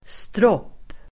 Ladda ner uttalet
stropp substantiv (vardagligt), stuck-up (conceited) person [informal]Uttal: [stråp:] Böjningar: stroppen, stropparDefinition: skrytsam och högdragen person